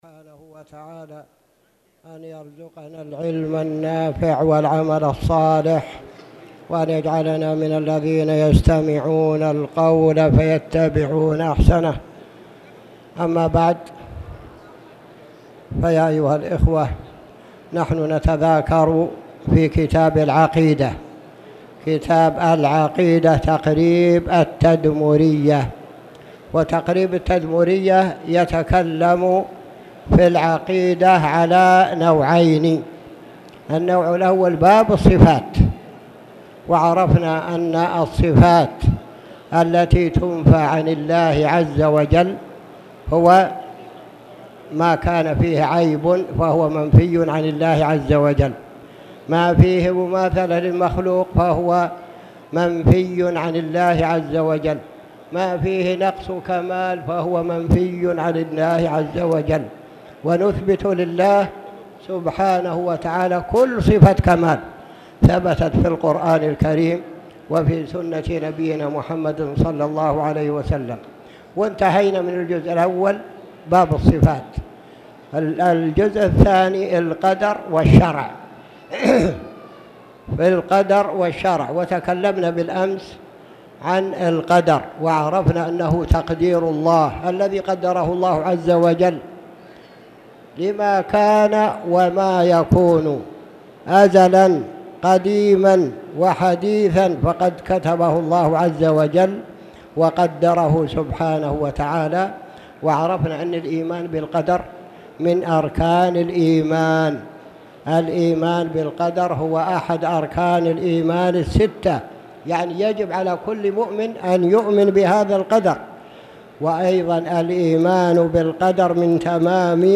تاريخ النشر ١٣ صفر ١٤٣٨ هـ المكان: المسجد الحرام الشيخ